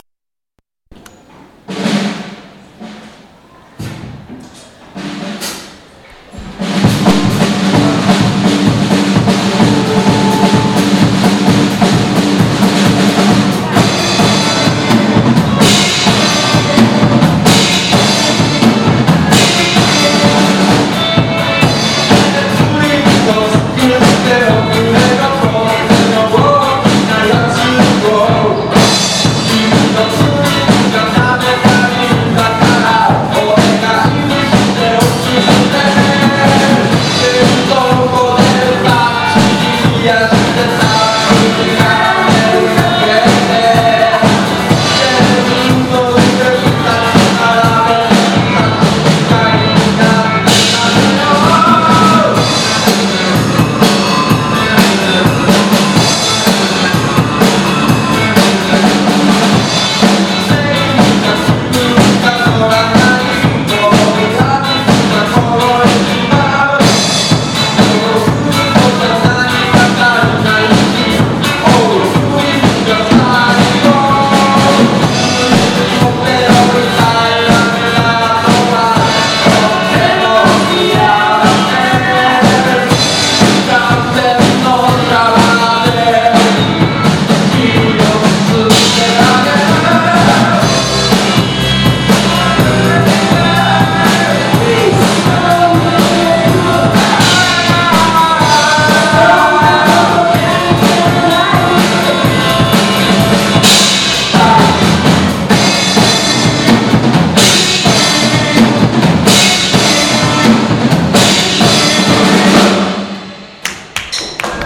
まあ、学祭ってこともあって場所は教室、ベニヤ板なんかで作った仮のステージでの演奏で、ステージ自体が常に演奏に共振して揺れているという、かなり環境のよろしくない中での演奏でして、正直、人前に聴かせられる演奏ではございません。
音源置き場に置いてある音源の中で、一番下手くそな演奏だと思います。